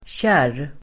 Ladda ner uttalet